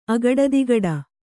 ♪ agaḍadigaḍa